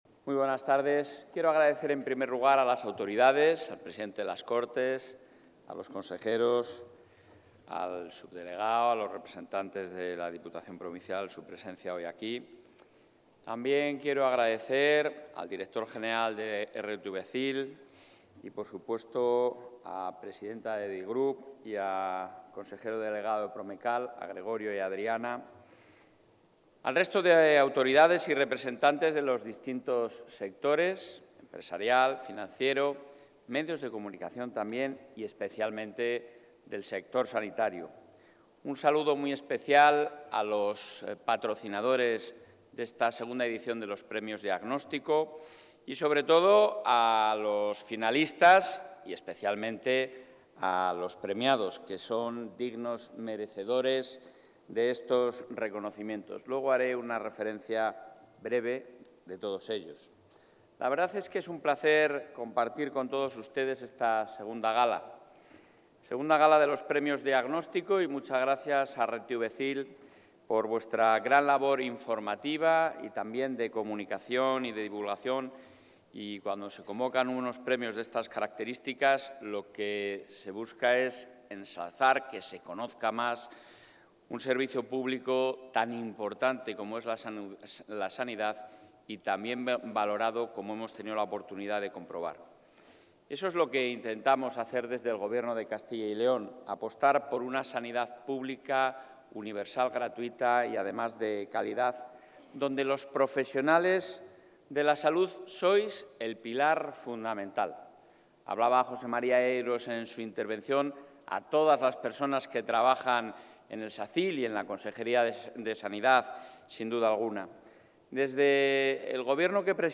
El presidente del Ejecutivo autonómico, Alfonso Fernández Mañueco, ha participado hoy en León a la II edición de...
Intervención del presidente de la Junta.